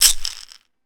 Index of /90_sSampleCDs/Roland L-CD701/PRC_Latin 2/PRC_Shakers
PRC BASKET 3.wav